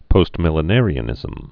(pōstmĭl-ə-nârē-ə-nĭzəm)